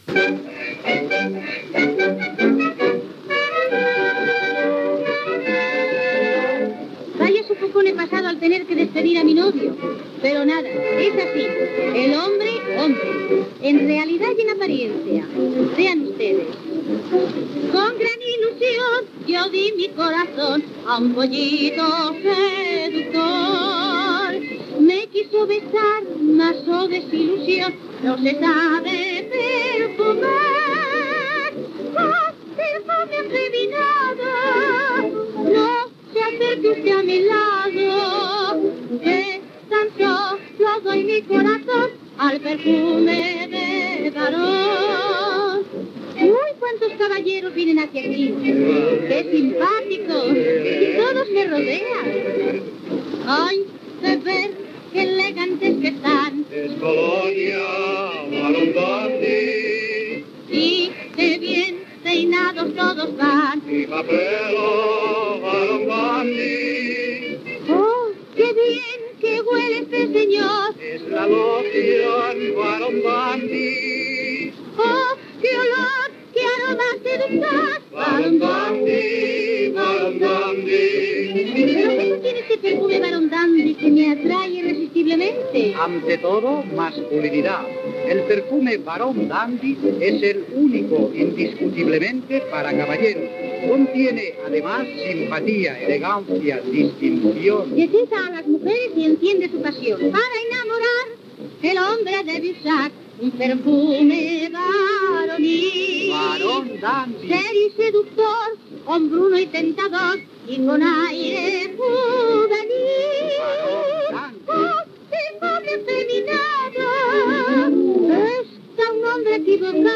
Cançó publicitària